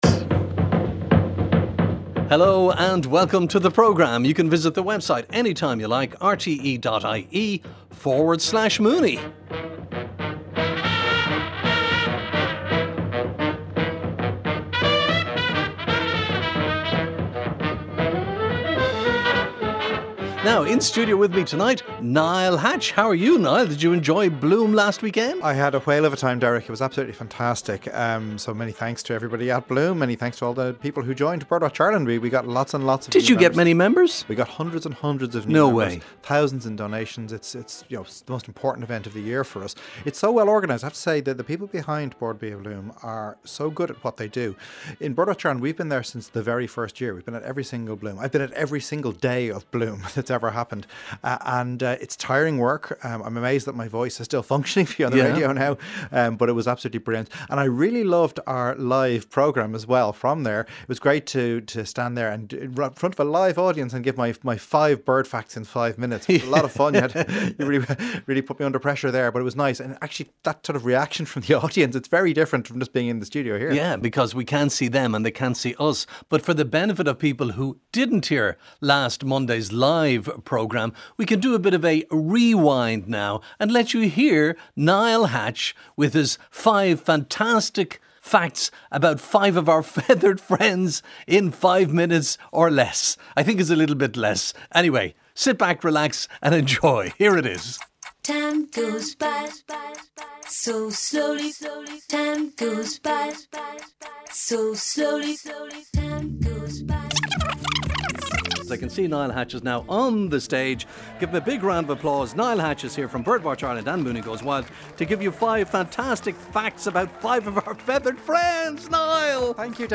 Were you one of the thousands of people who attended Bord Bia Bloom over the June Bank Holiday period?